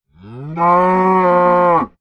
cow3.ogg